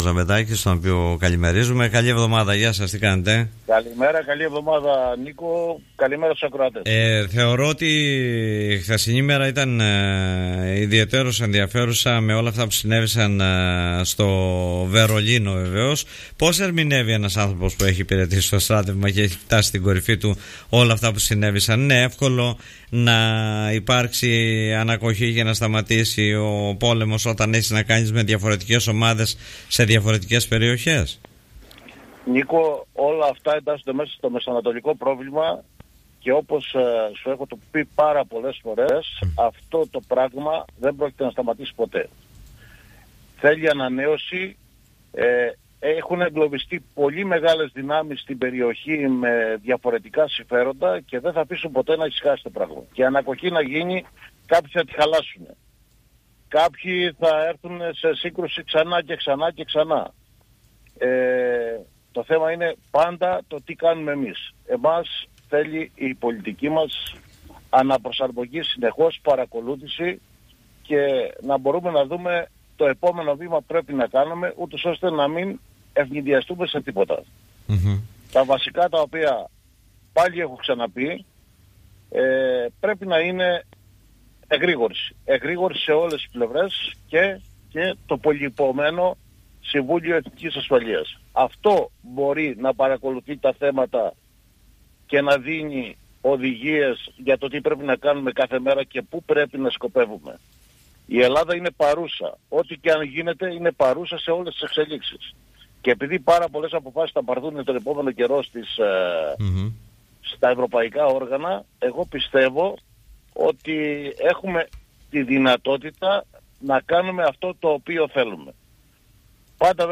μίλησε στην εκπομπή “Δημοσίως” του politica 89.8